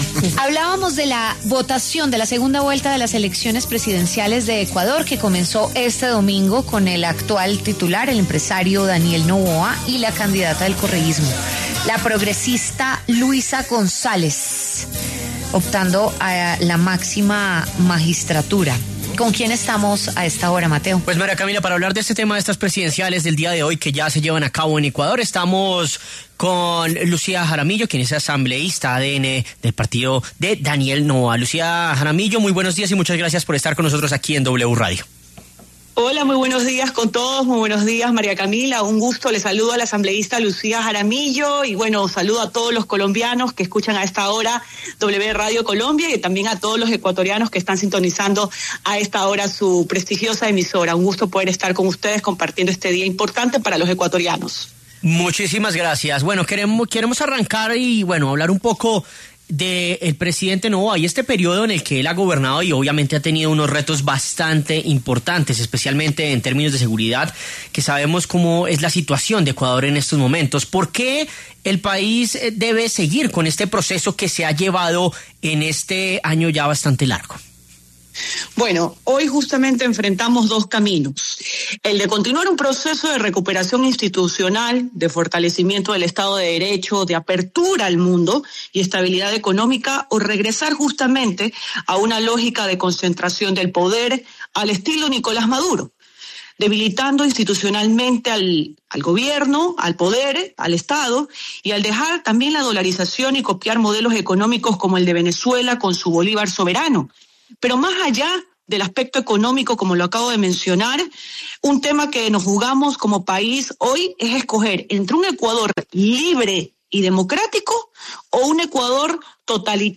Lucía Jaramillo, miembro de la Asamblea Nacional de Ecuador, se refirió en W Fin de Semana a las elecciones generales en su país.